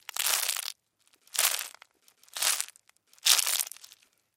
Шум гусеницы, поедающей лист